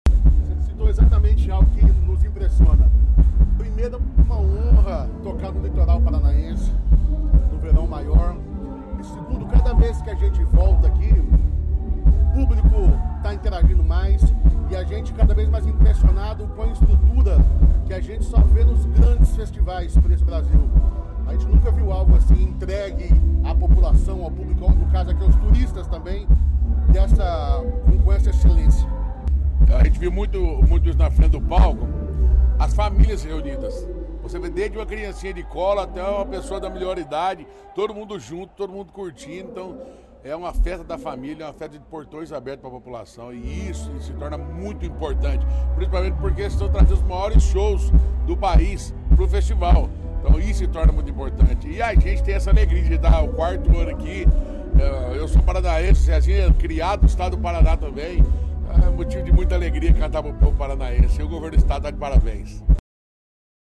Sonora da dupla César Menotti e Fabiano sobre o show no Verão Maior Paraná em Matinhos